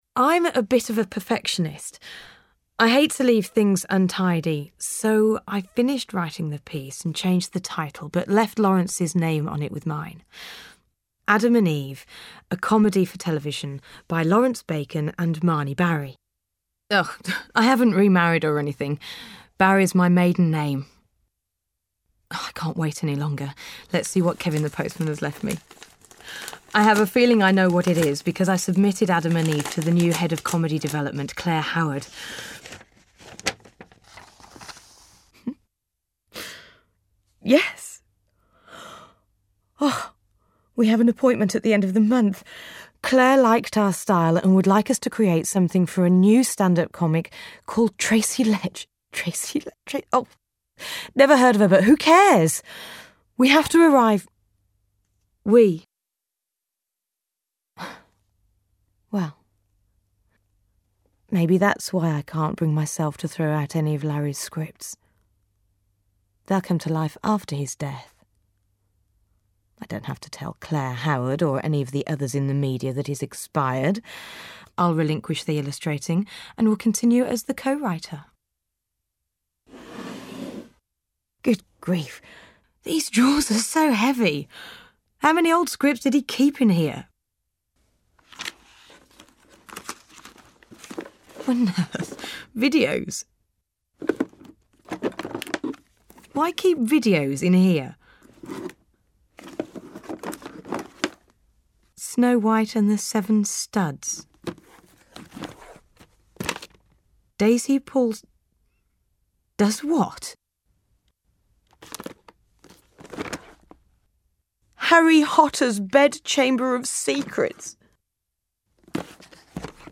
Voiceover – Narrative